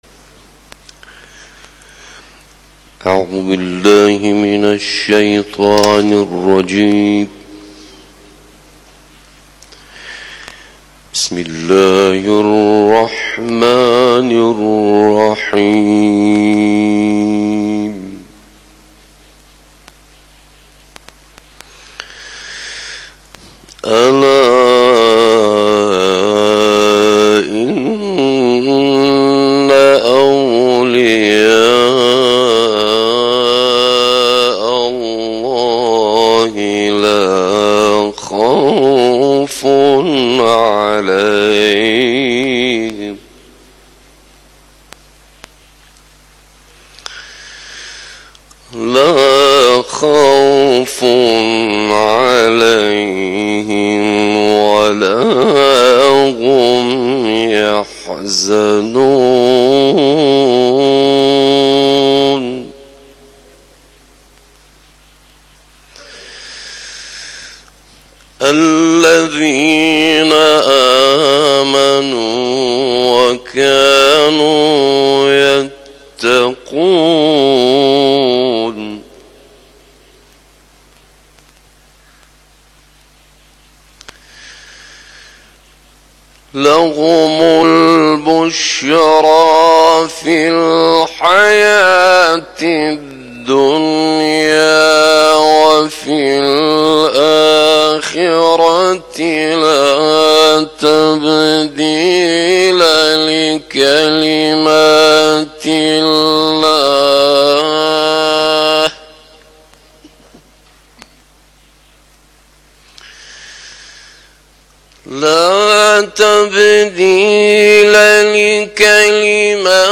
در کرسی اذان‌گاهی صبح آستان عبدالعظیم(ع)
قاری بین‌المللی کشورمان به تلاوت آیاتی از کلام الله مجید پرداخت.
در یازدهمین کرسی تلاوت اذانگاهی صبح که در جوار مضجع شریف حضرت عبدالعظیم(ع) برگزار شد، به تلاوت سوره‌های مبارکه یونس، فصلت و کوثر پرداخت که در ادامه صوت آن تقدیم می‌شود.